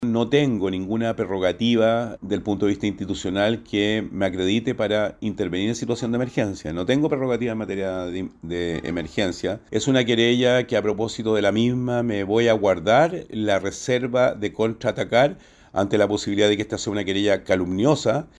Ante esta situación, el gobernador de la Región de Valparaíso, Rodrigo Mundaca, se defendió argumentando que él no tiene las facultades para intervenir en emergencias.